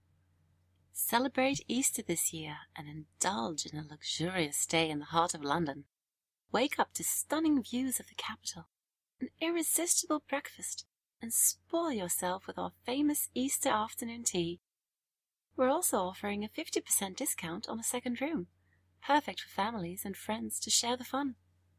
Native English speaker.
Experienced voice-over artist with professional studio and sound editing skills. Clear, neutral (international) accent. Voice is warm, authentic, reassuring and has a very personal sound. British and American accents.
Sprechprobe: Industrie (Muttersprache):